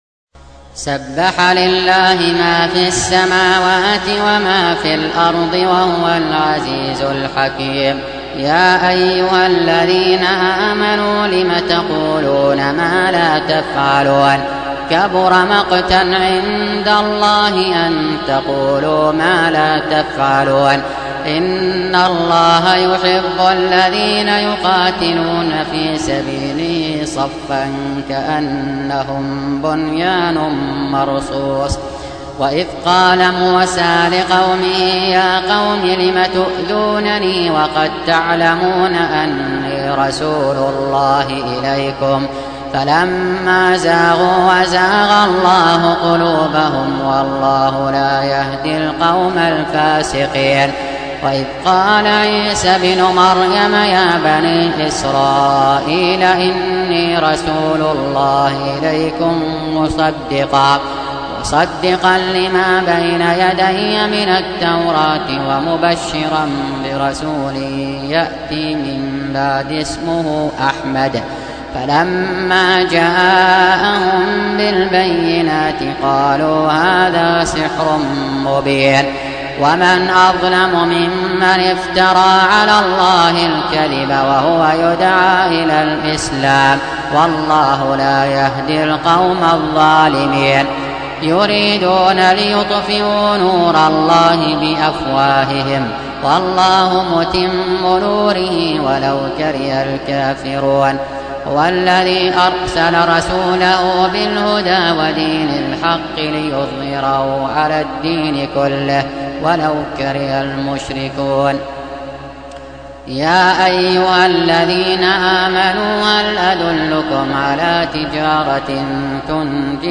Surah Repeating تكرار السورة Download Surah حمّل السورة Reciting Murattalah Audio for 61. Surah As-Saff سورة الصف N.B *Surah Includes Al-Basmalah Reciters Sequents تتابع التلاوات Reciters Repeats تكرار التلاوات